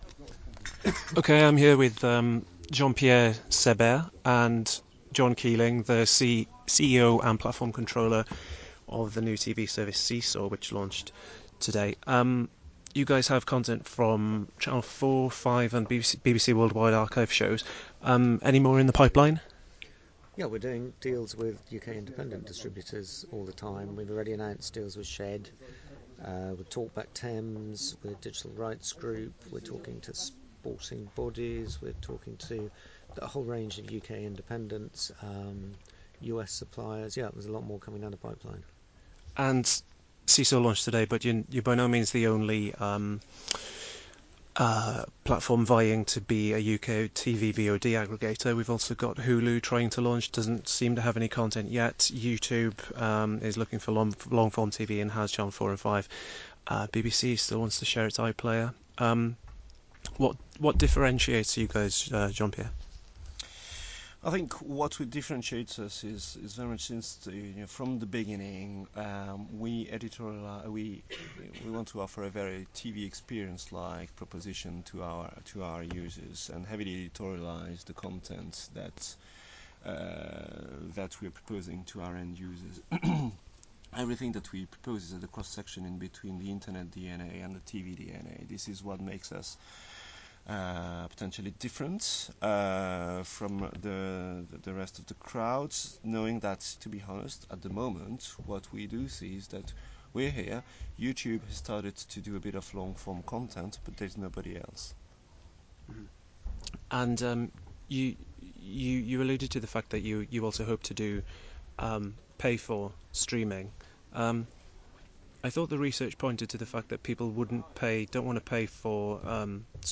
Interview with SeeSaw bosses
98692-interview-with-seesaw-bosses.mp3